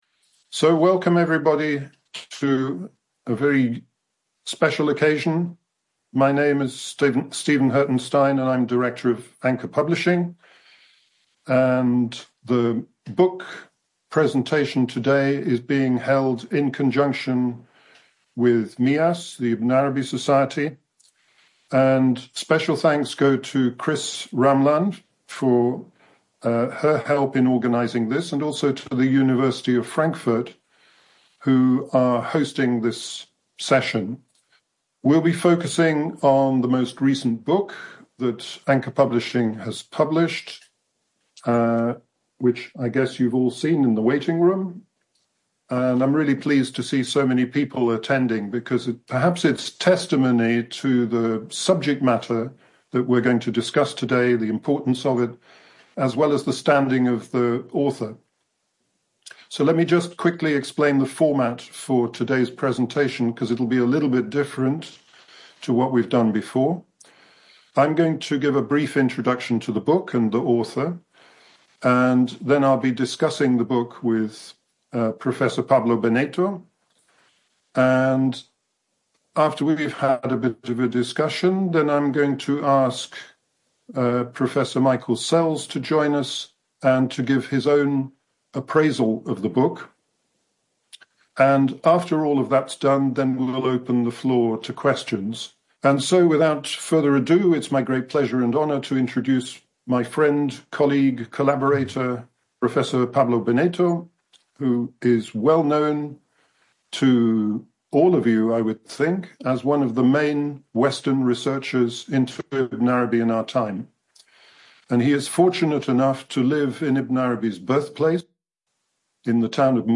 This podcast offers a sampling of talks given by researchers, teachers, translators, and lovers of Ibn Arabi, given at the annual symposia, and in online seminars.